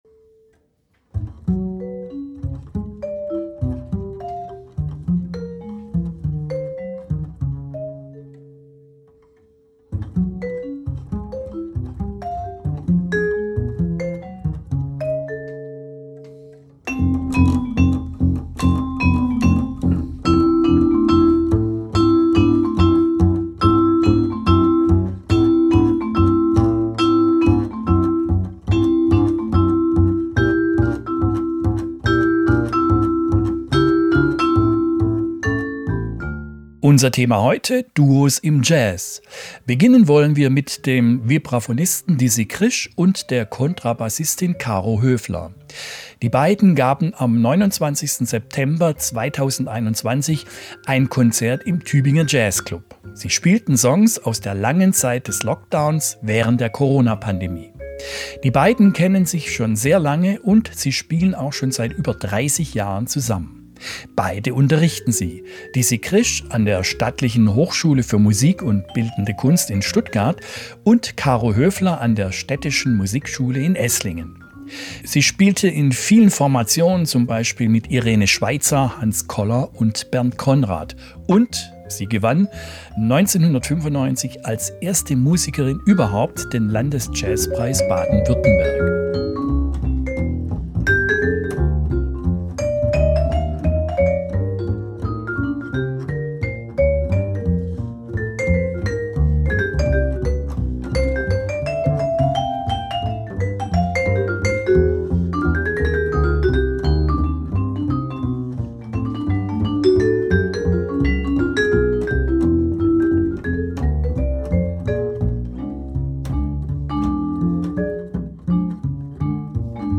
live im Tübinger Jazzclub, 29.09.21, Teil 2 (670)
Musik